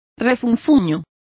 Complete with pronunciation of the translation of snarl.